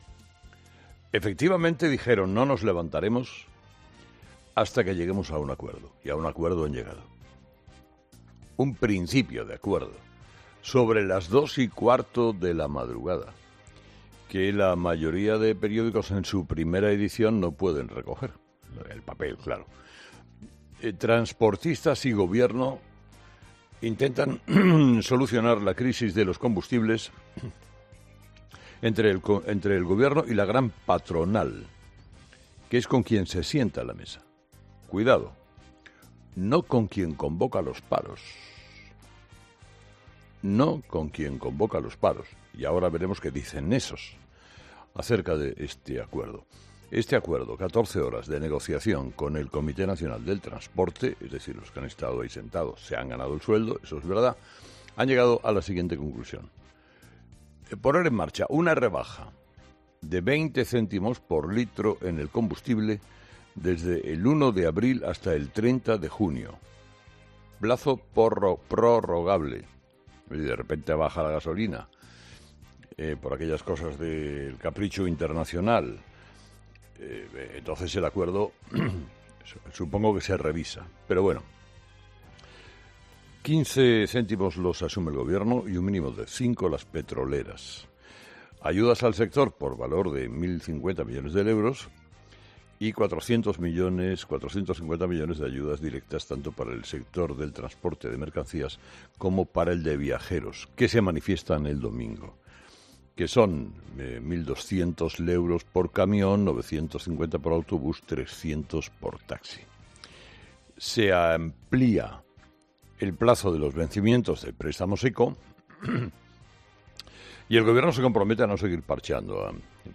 Carlos Herrera, director y presentador de 'Herrera en COPE', ha comenzado el programa de este viernes analizando las principales claves de la jornada, que pasan, entre otros asuntos, por el acuerdo que Gobierno y transportistas han llegado esta pasada madrugada para poner fin a la huelga que afecta desde hace más de diez días al sector.